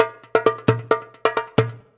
Index of /90_sSampleCDs/USB Soundscan vol.56 - Modern Percussion Loops [AKAI] 1CD/Partition C/17-DJEMBE133
133DJEMB01.wav